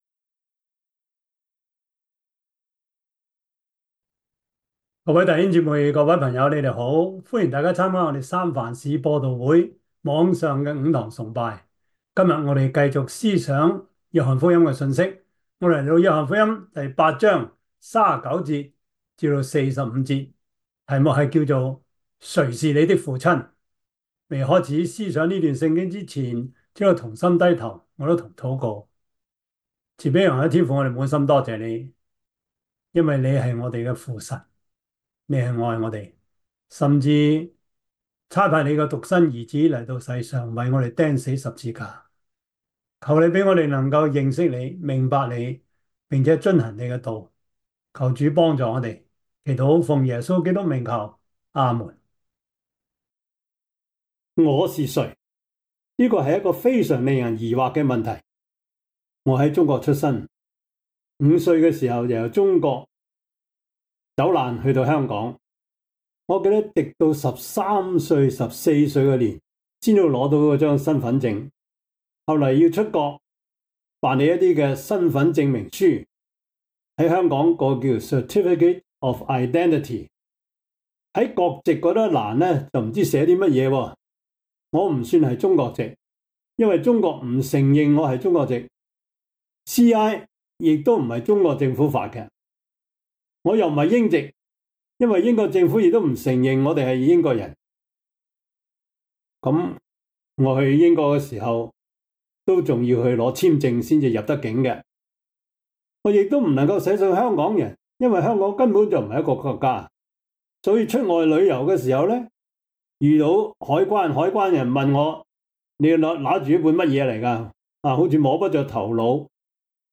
約翰福音 8:39-45 Service Type: 主日崇拜 約翰福音 8:39-45 Chinese Union Version
Topics: 主日證道 « 安息日的主 快樂家庭之點止咁簡單 »